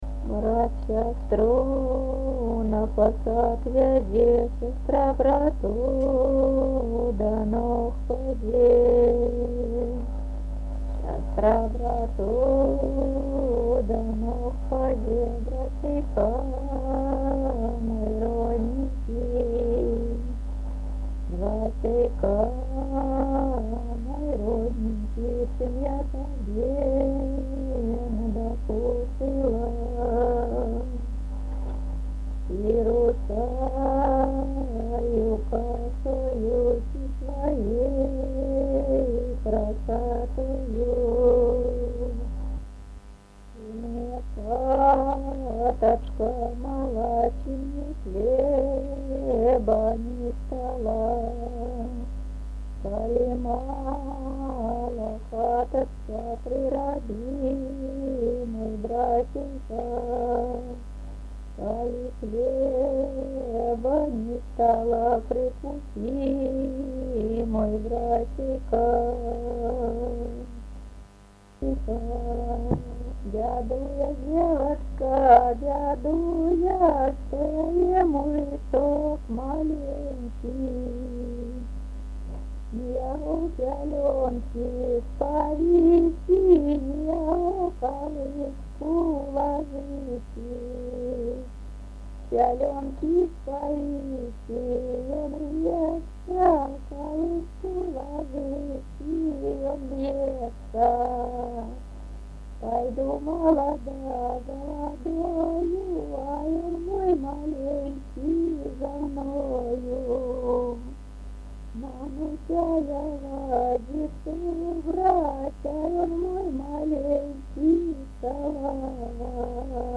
Тема: ЭБ БГУ::Беларускі фальклор::Паэзія сямейна-абрадавага комплексу::вясельныя песні
Месца запісу: в.Аляхновічы